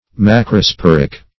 Macrosporic \Mac`ro*spor"ic\, a.
macrosporic.mp3